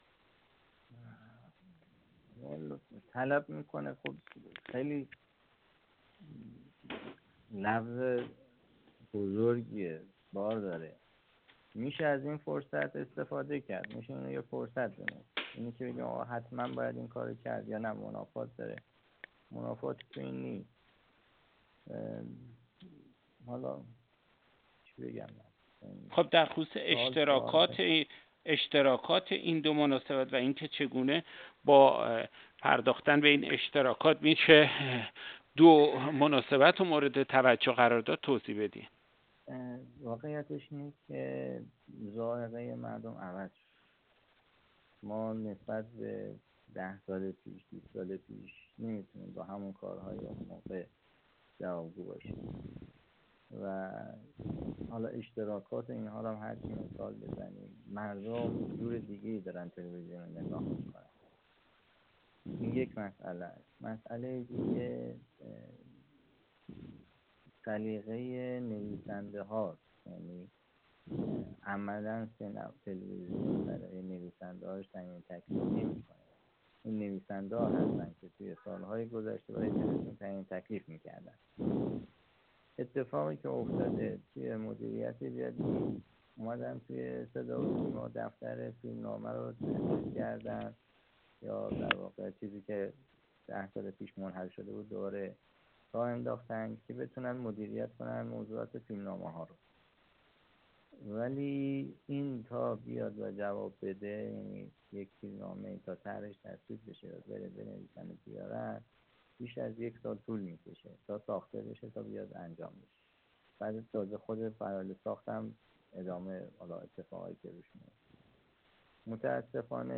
یک کارشناس رسانه: